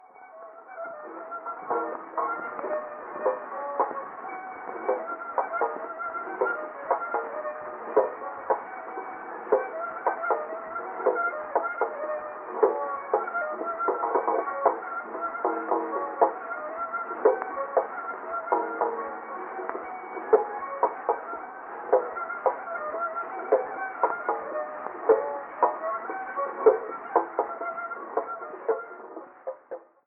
[14] Figures 19–22 are transcriptions of tombak lines from recordings of older styles.
Another 3/4 meter groove ostinato (top) with its variations (bottom) in 6/8, from a 1912 recording in Tehran